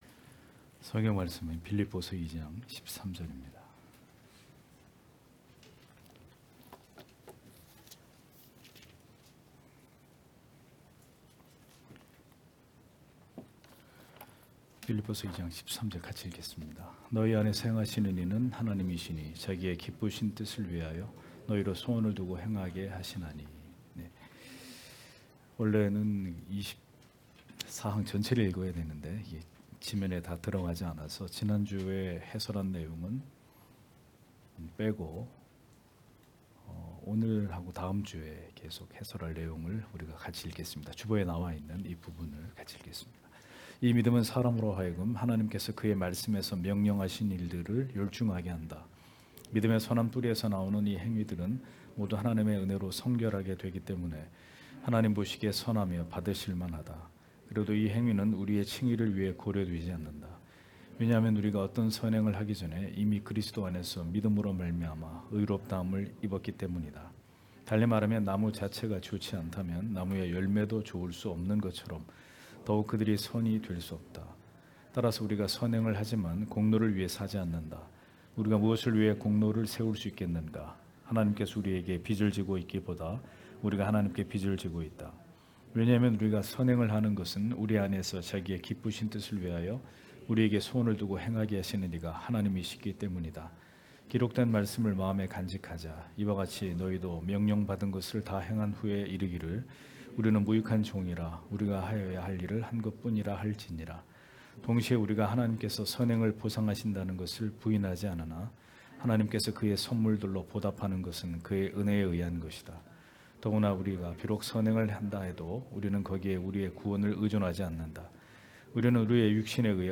주일오후예배 - [벨직 신앙고백서 해설 26] 제24항 우리의 성화와 선행 (빌 2장13절)